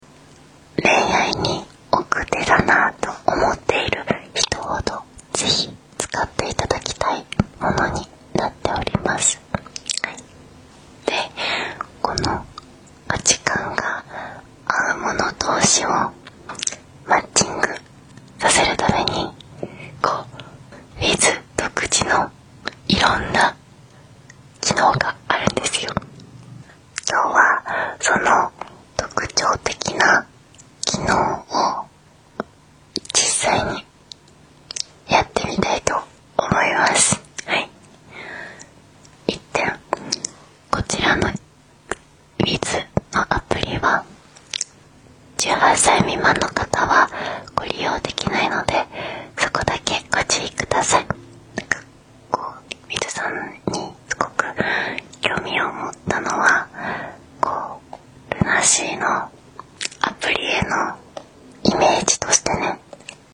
Breathy ASMR_Sourse.wav